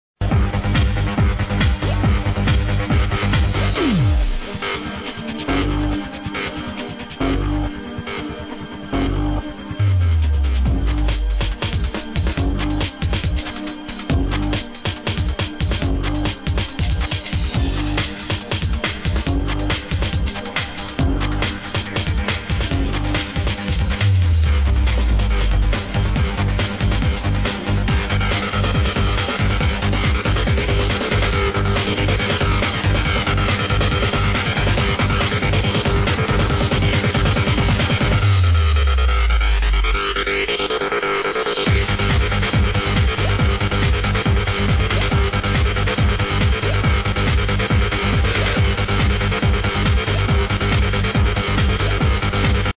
live mix
It does sound like a Warp Brothers production.